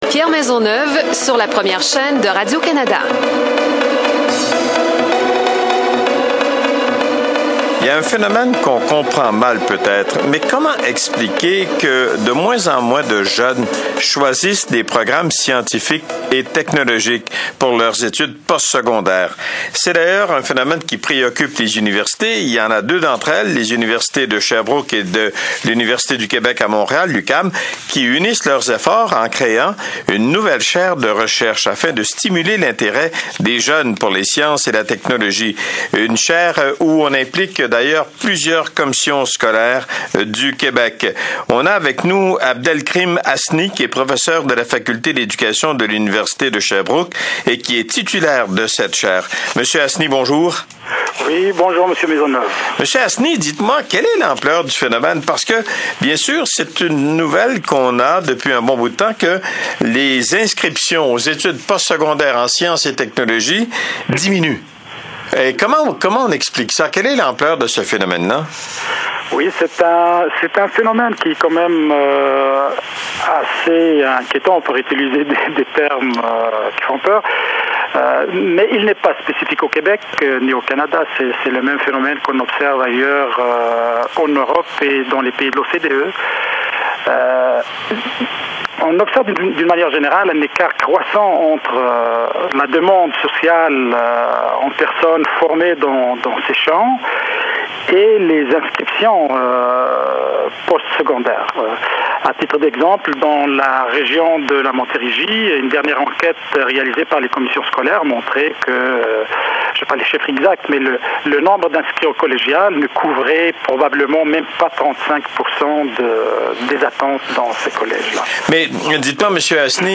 Entrevue (6.82 Mo)